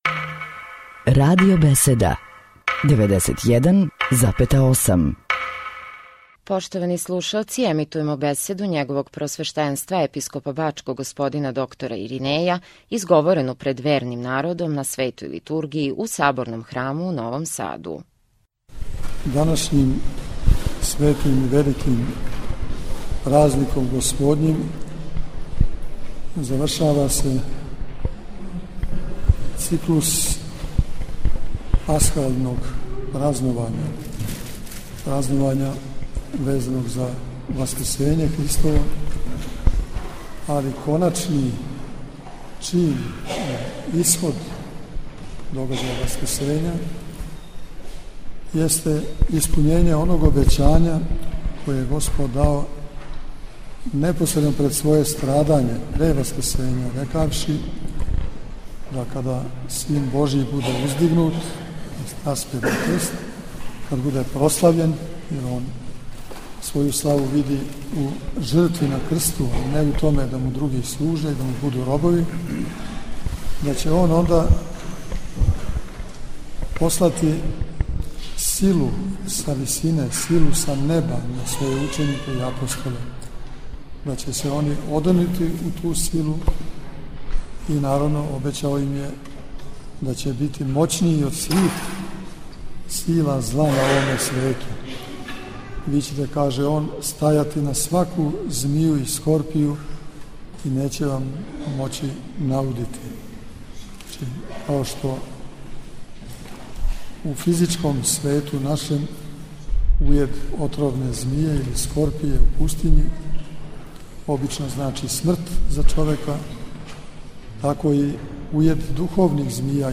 Нови Сад – Свештеним евхаристијским сабрањем на празник Педесетнице, у Саборној цркви у Новом Саду, уз саслужење четири свештеника, осам ђакона и мноштва верних, началствовао је Епископ новосадски и бачки Господин др Иринеј.
• Беседа Епископа Иринеја: